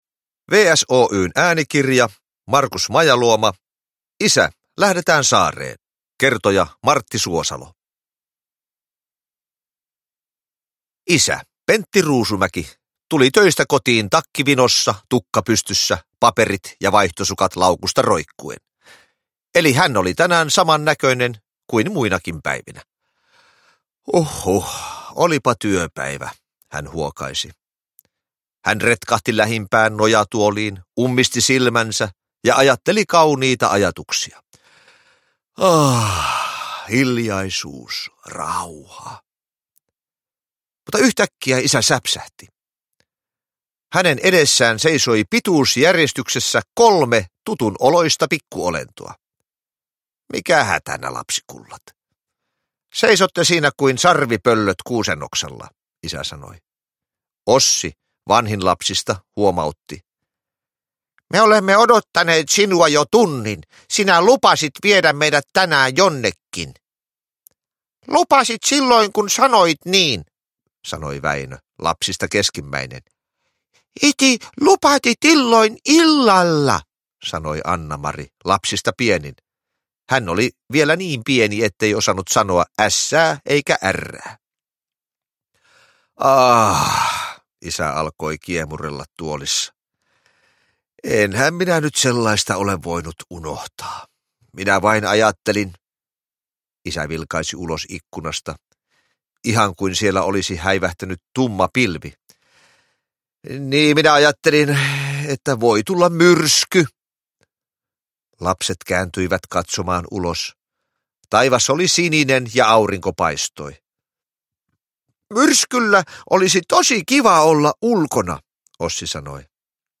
Isä, lähdetään saareen! – Ljudbok – Laddas ner
Näyttelijä Martti Suosalo on kertojana viisiosaisesta sarjasta tehdyissä äänikirjoissa.
Uppläsare: Martti Suosalo